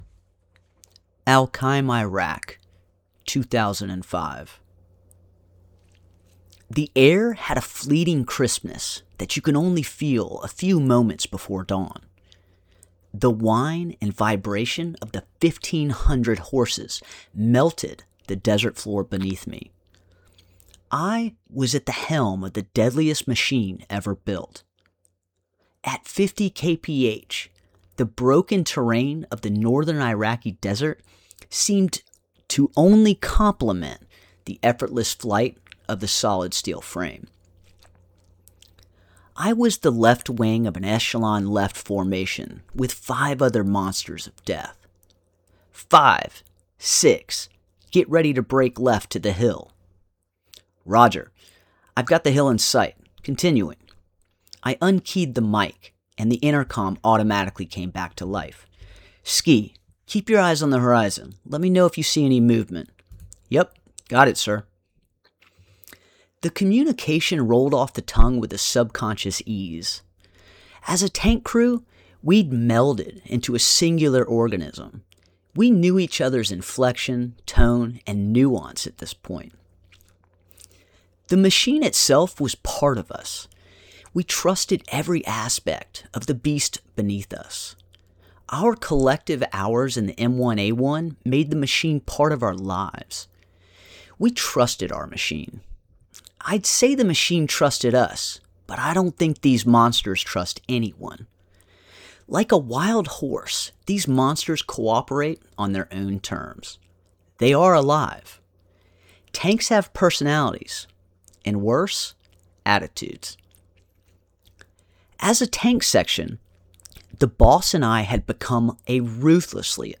Tiger 5 Audio Book – almost here